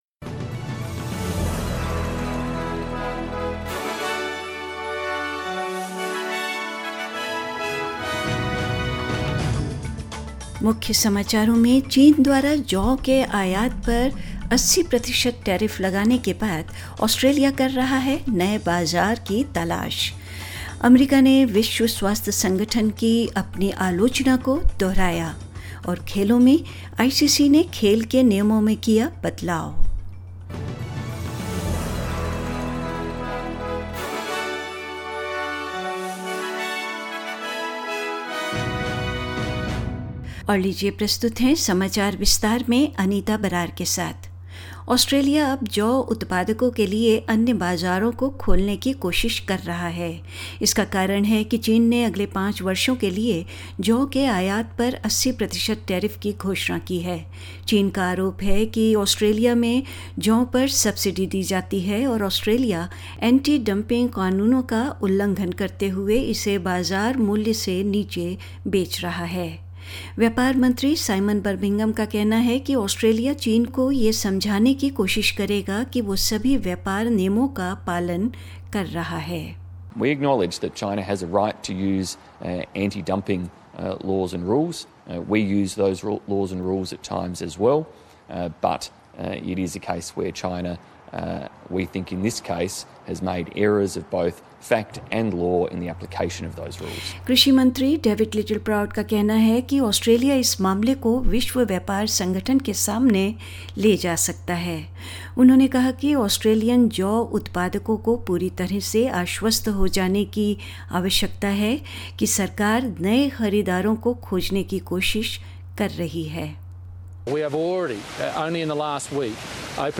Hindi News 19 May 2020